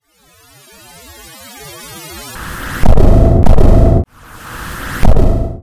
连续激光射击
描述：太空飞船激光枪连续射击
标签： 飞船 激光 武器 战斗 攻击 游戏
声道单声道